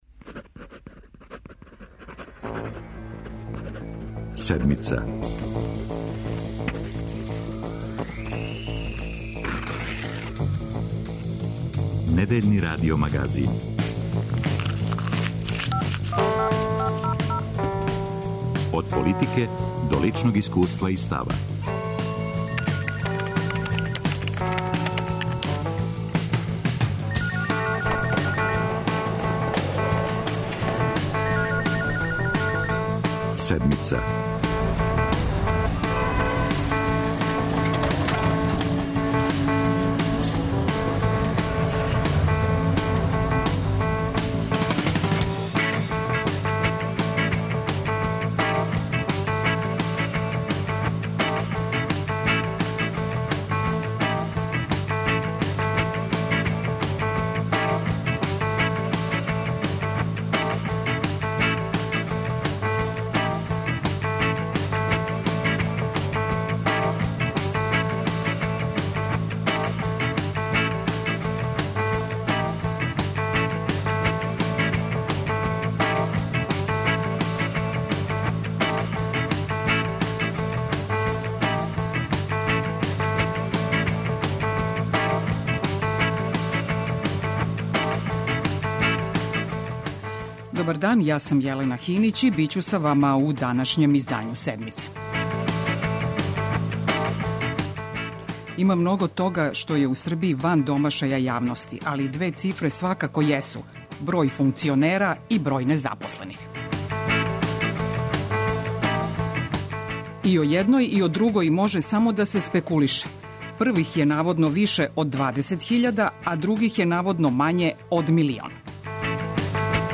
Гост - директорка Агенције за борбу против корупције Татјана Бабић.
Емитујемо и репортажу о сезонцима из Србије и региона који су окончали вишемесечни посао на црногорској обали.